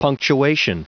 Prononciation du mot punctuation en anglais (fichier audio)
Prononciation du mot : punctuation